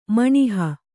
♪ maṇiha